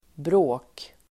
Uttal: [brå:k]